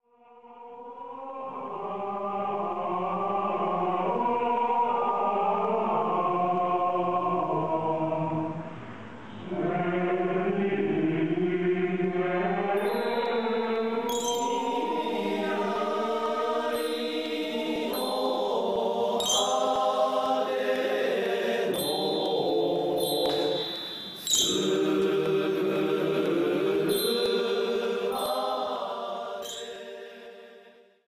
前にグレゴリオ聖歌と真言宗の和讃を
Download:44228_1.mp3 : 365 KB 　関係者の素人のライブ録音なので音悪し。